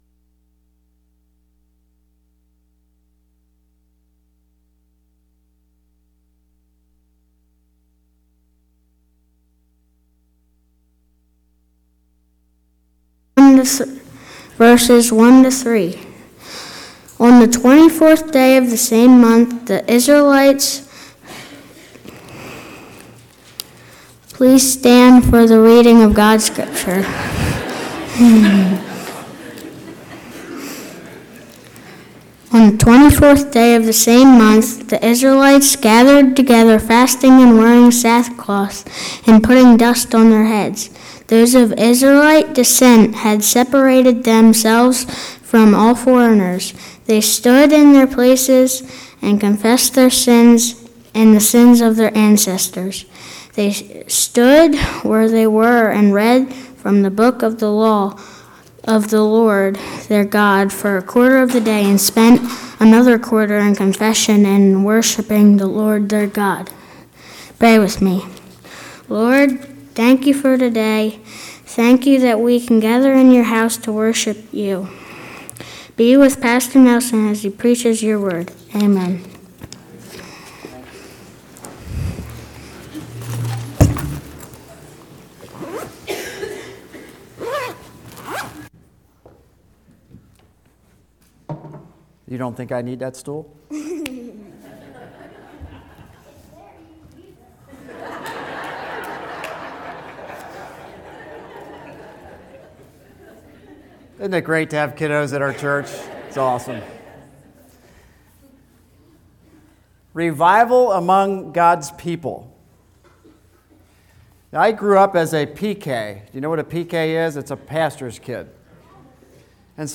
A message from the series "February 2026."